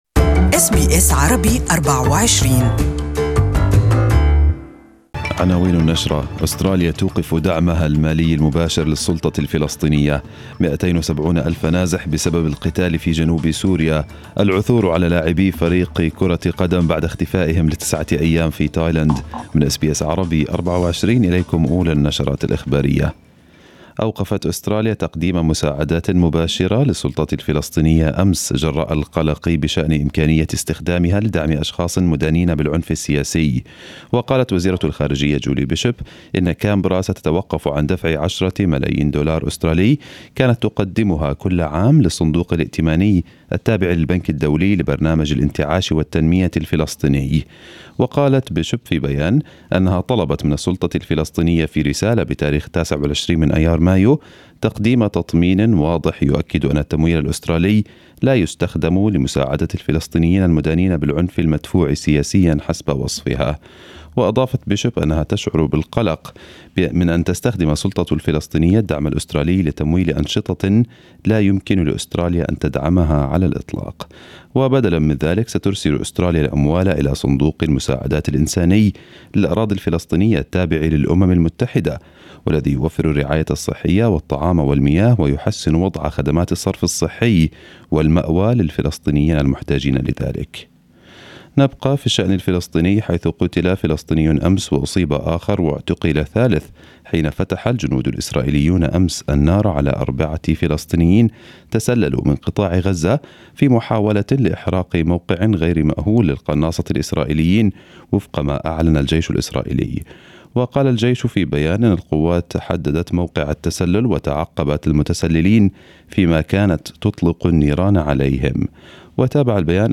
Arabic News Bulletin 03/07/2018